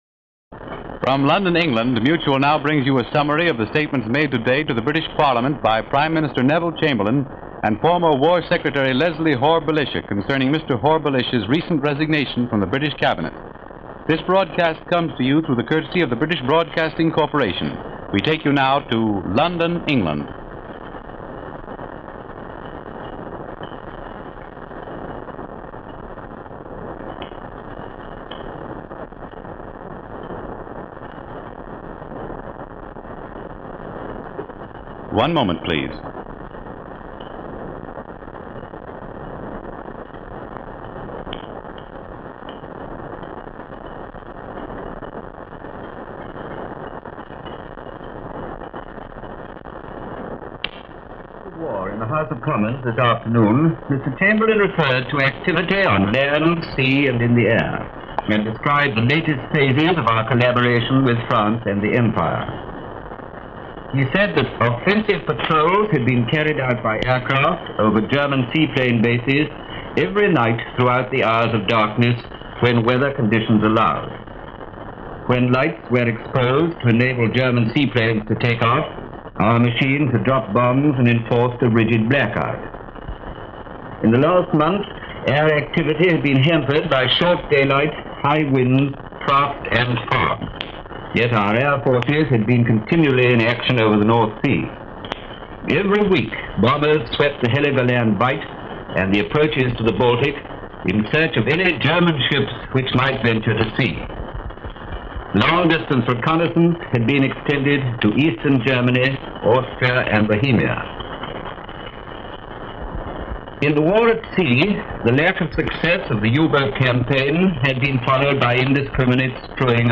January 1940 - the Matter of Leslie Hore-Belisha - News from London via Shortwave on the resignation and reaction for Prime Minister Neville Chamberlain.
BBC-Home-Service-News-January-1940.mp3